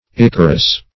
Ichorous \I"chor*ous\ ([imac]"k[o^]r*[u^]s), a. [Cf. F.